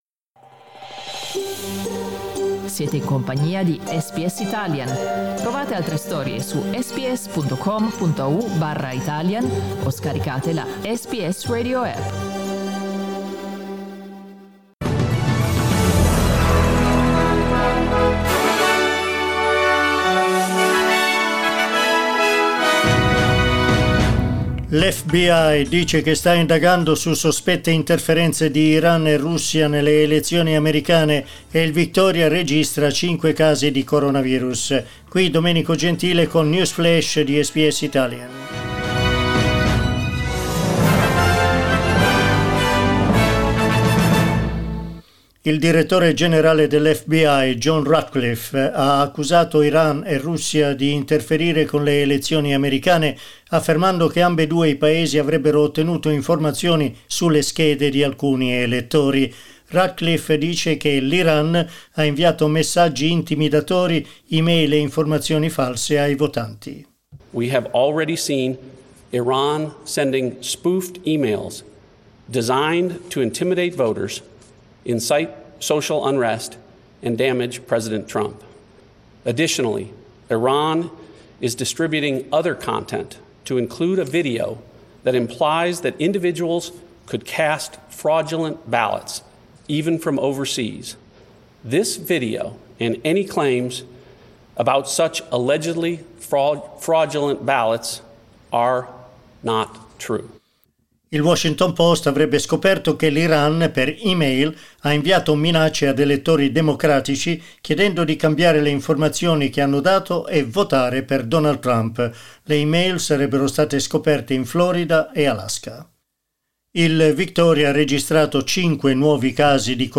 Aggiornamento delle notizie di SBS Italian.